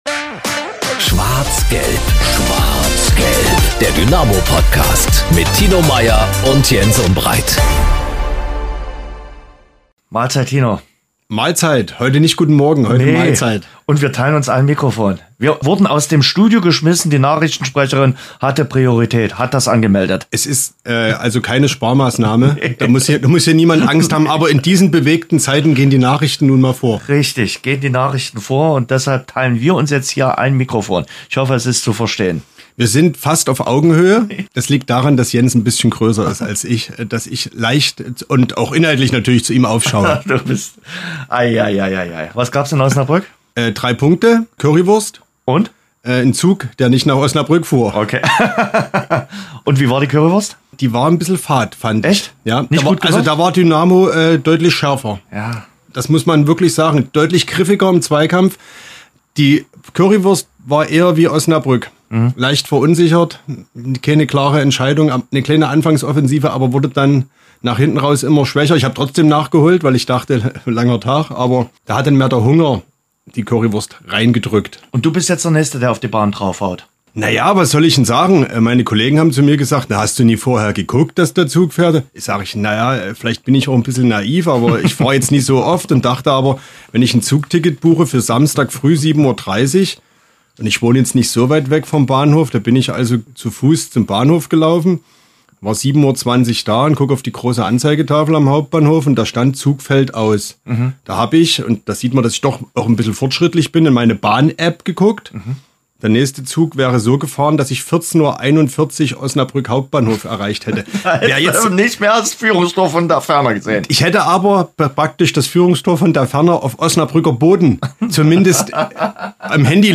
Ein Gespräch über Erfolge, Herausforderungen und die tiefe Verbindung zum Verein.